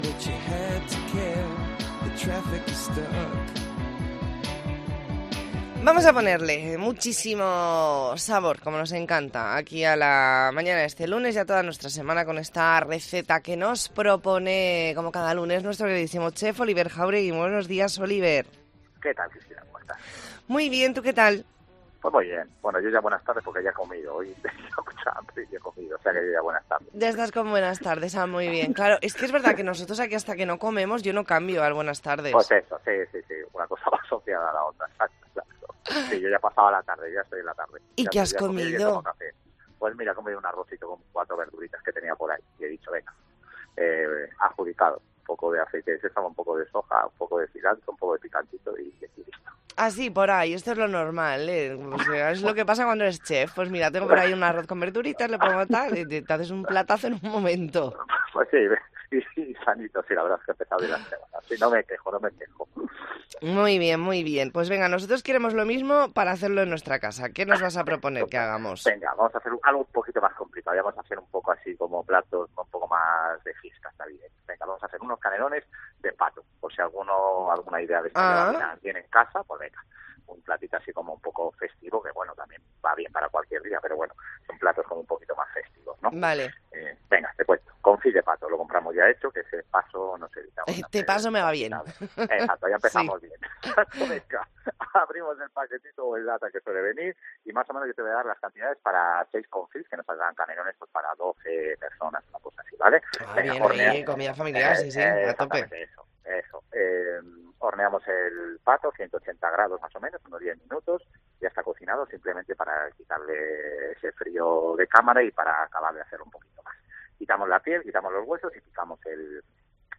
Entrevista en La Mañana en COPE Más Mallorca, lunes 11 de diciembre de 2023.